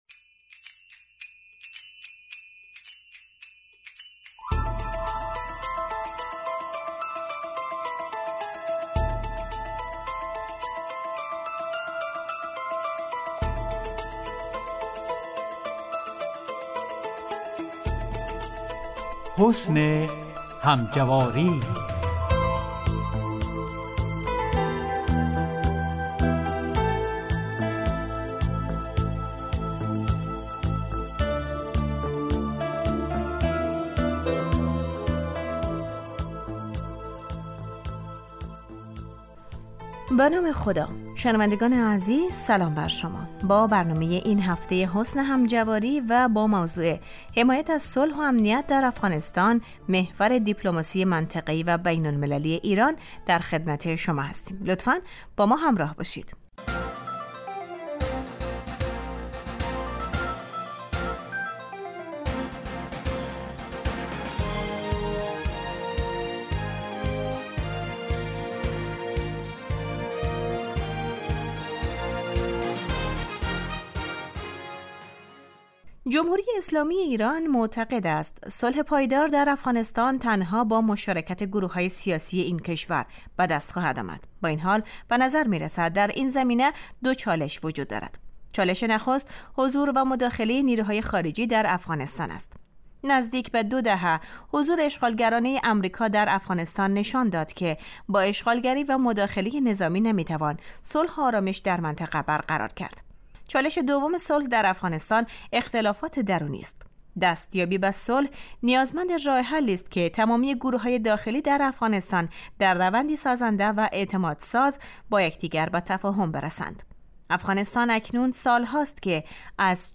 حسن همجواری برنامه ای 15 دقیقه ای است که در روزهای سه شنبه و پنج شنبه ساعت 14:15 به روی آنتن می رود .